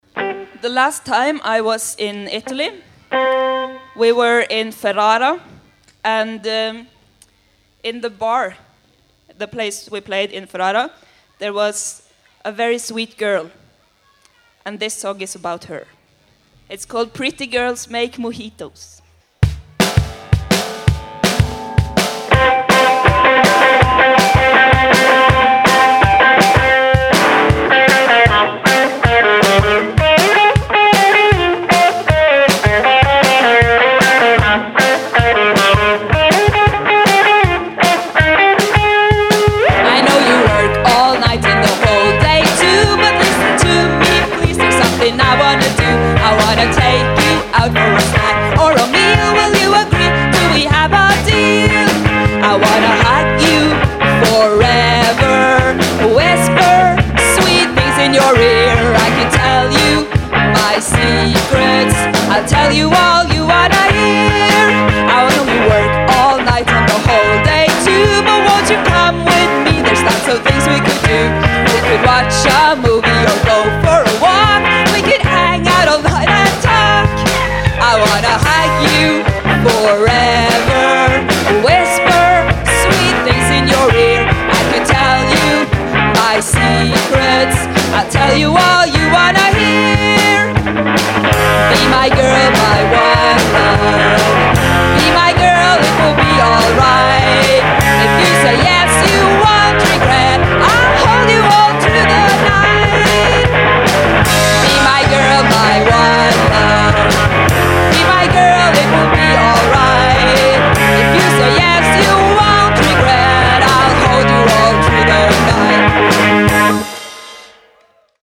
live in Brescia